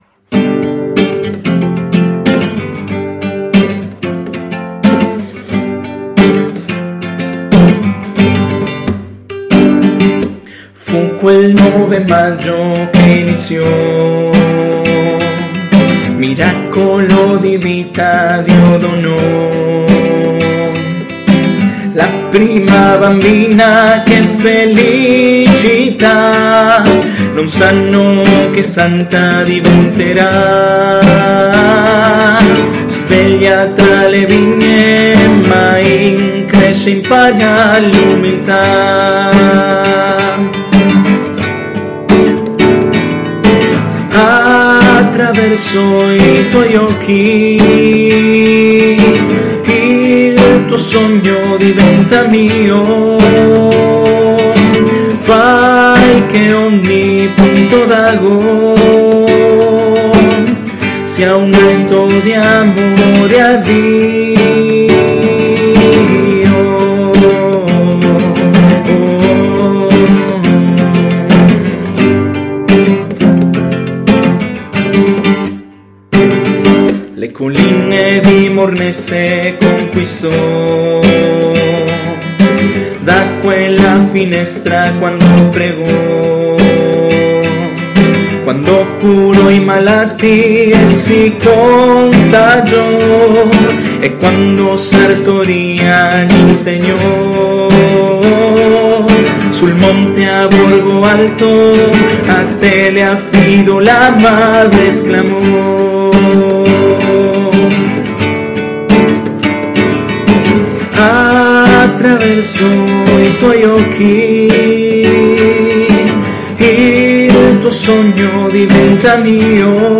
Inno – Salesiane di Don Bosco